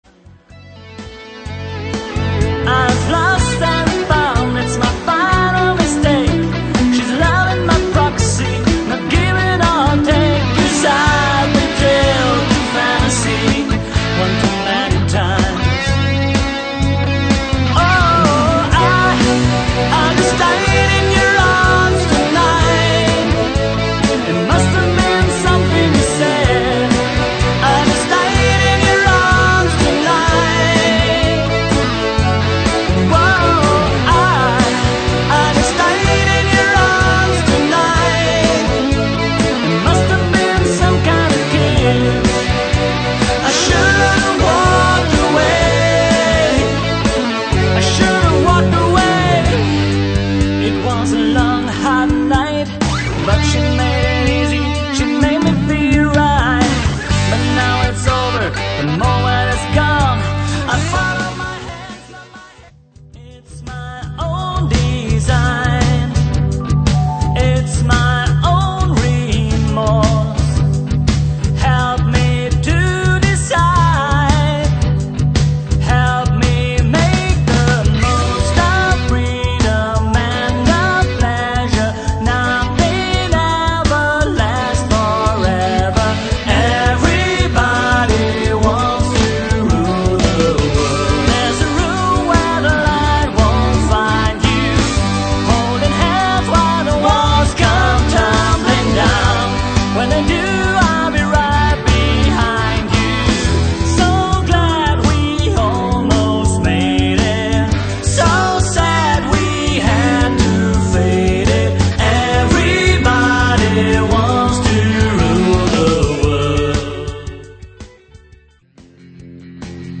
Fotogalerie: Demo Songs: Big80s_demozusammenschnitt.mp3